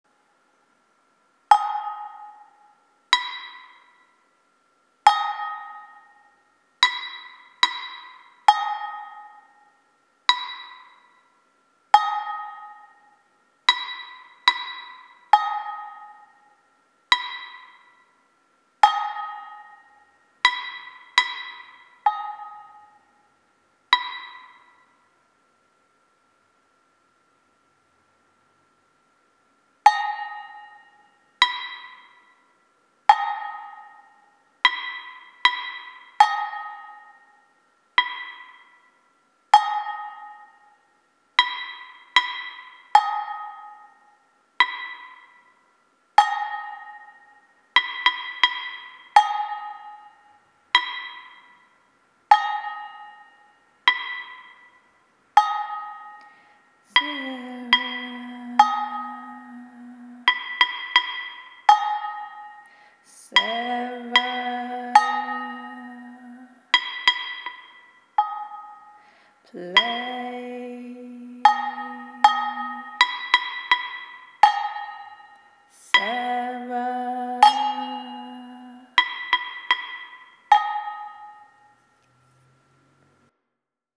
PMLD KS3 Unit 5 Being Human - Resource Buddhist Gongs audio
pmld-ks3-unit-5-being-human---resource-buddhist-gongs-audio.m4a